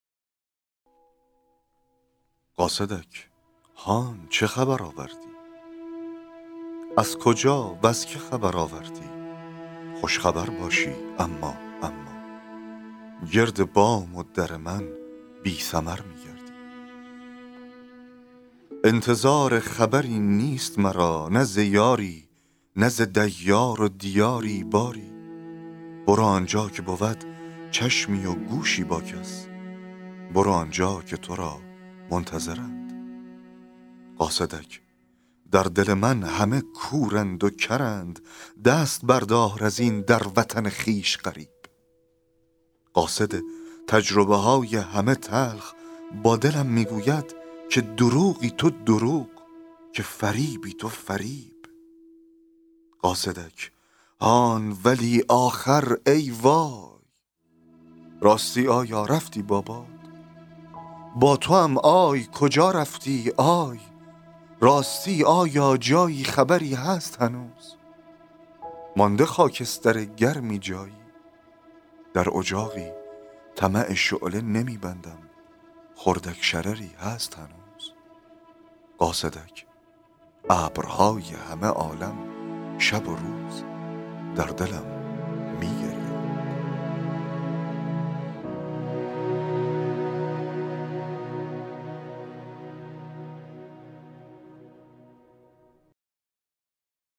فایل صوتی دکلمه شعر قاصدک